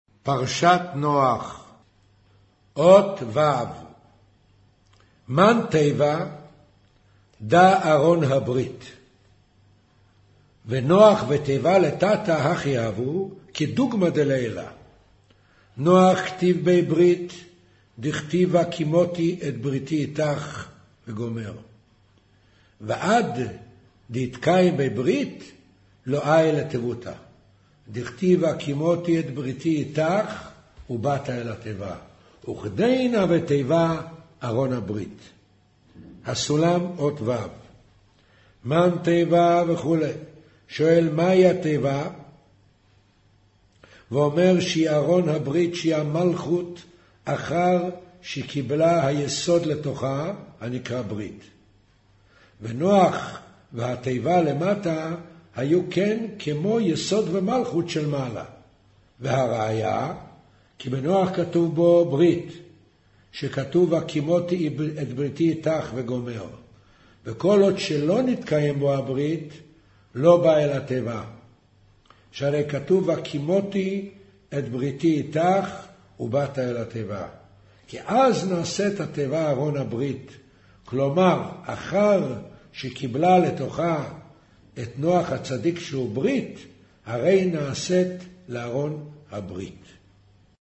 קריינות זהר